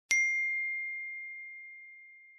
1. iPhone Notification Sound
It is simple, clean, and instantly recognizable.
iphone_notification_sound_effect.mp3